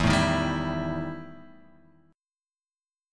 EmM7bb5 chord {0 0 1 0 x 0} chord